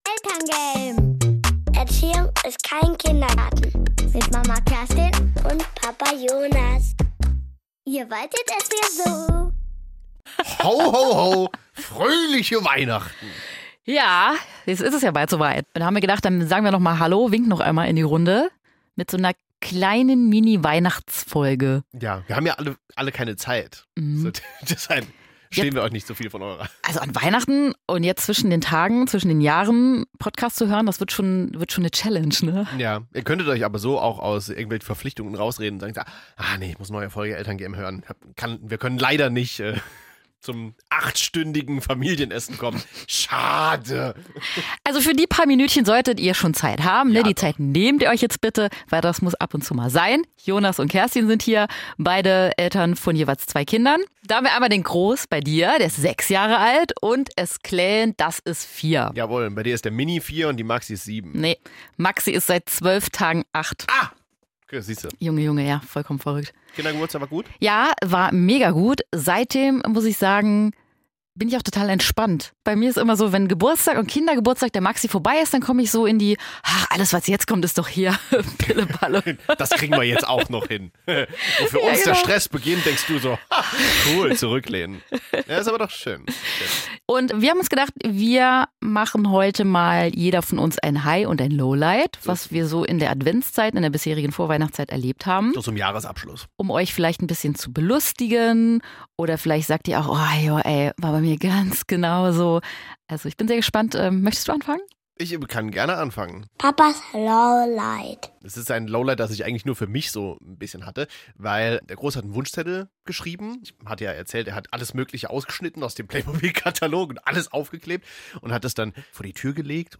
Ungeschönt, aber immer mit einer guten Portion Humor